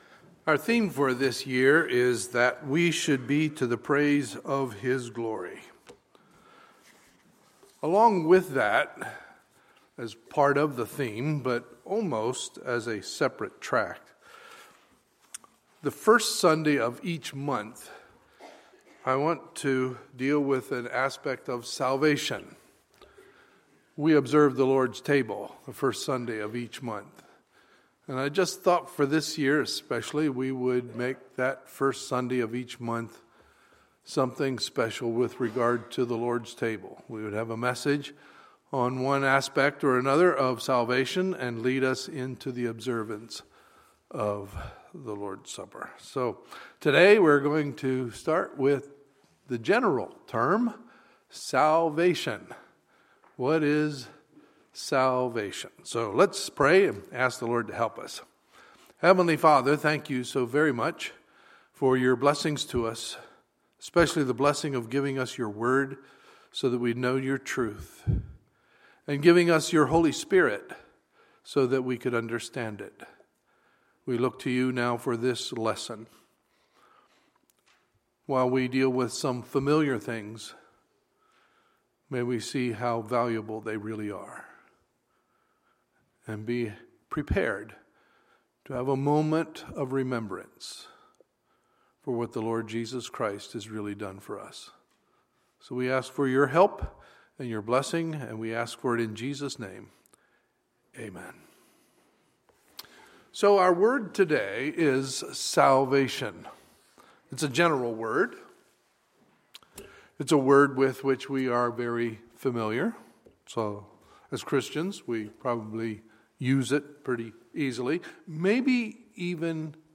Sunday, February 7, 2016 – Sunday Morning Service